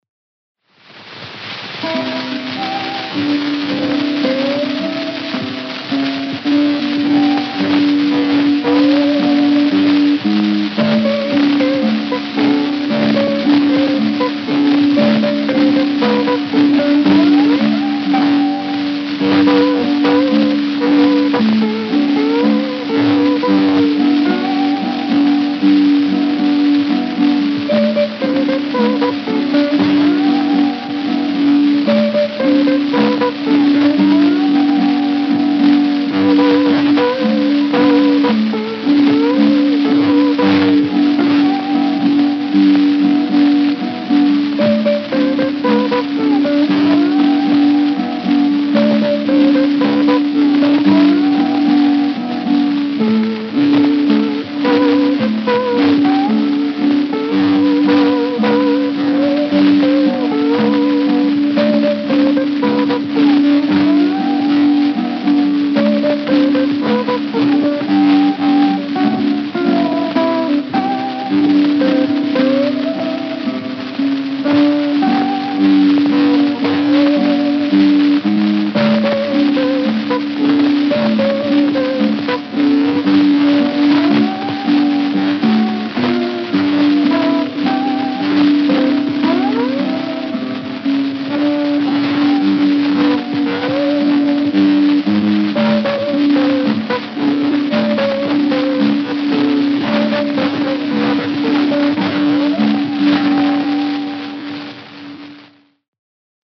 Guitar Duet